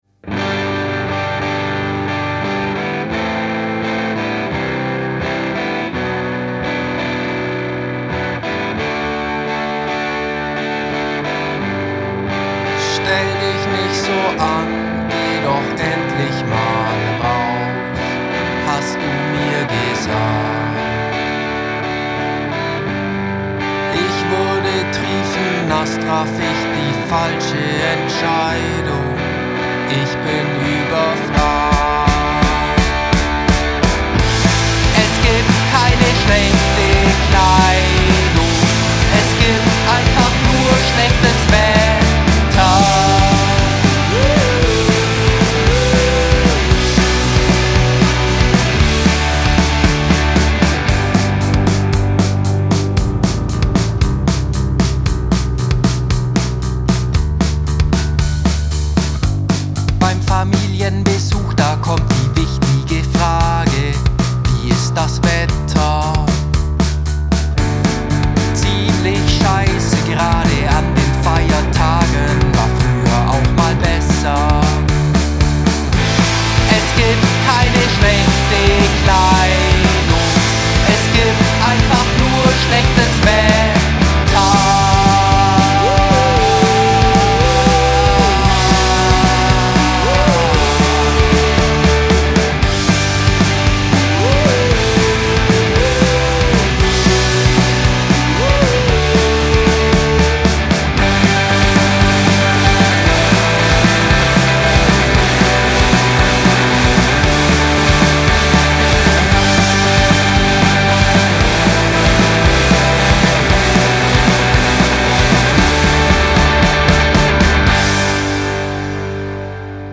Gitarre
Bass
Schlagzeug
Es wird wird laut, verrückt, schrammlig.